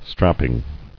[strap·ping]